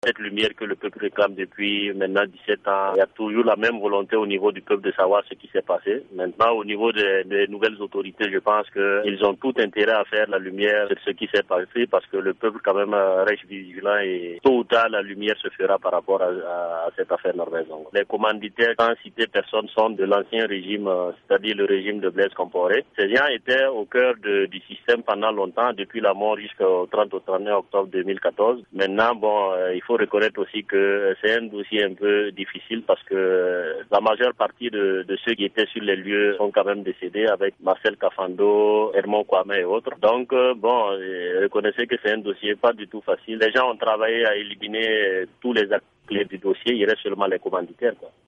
dans une interview à VOA Afrique